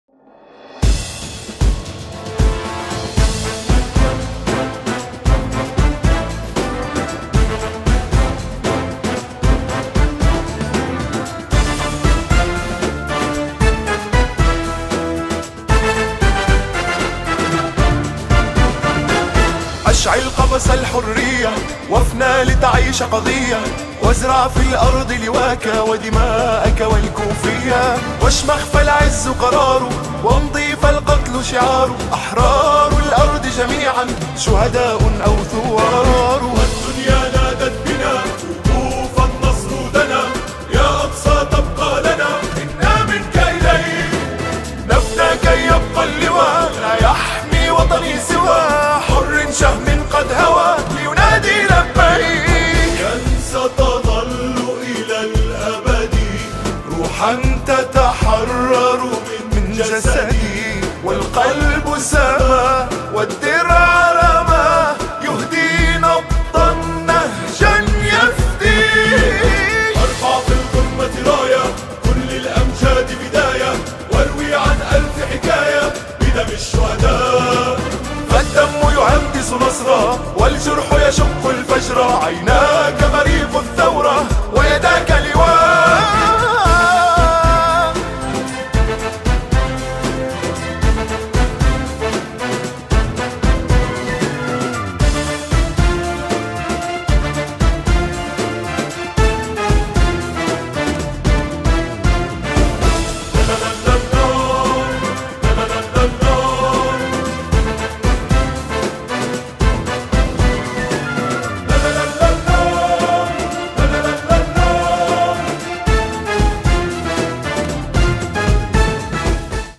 نشيد